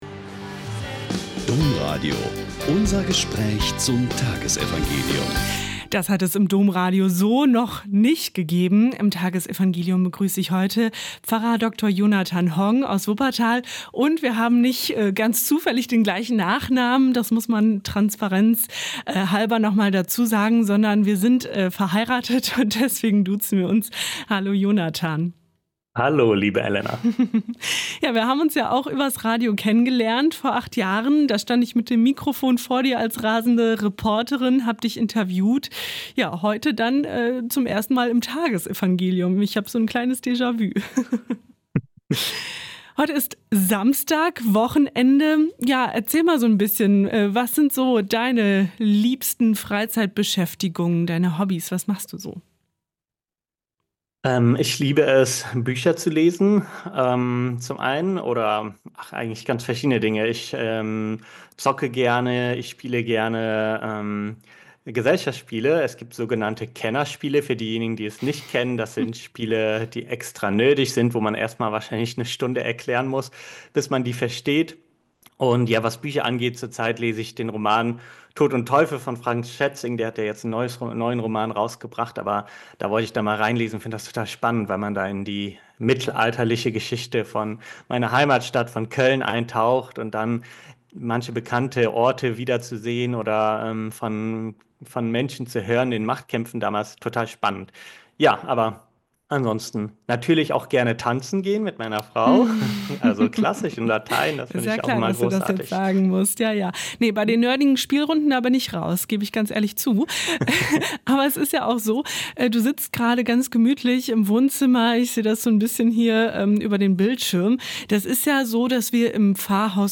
Mk 4,35-41 - Gespräch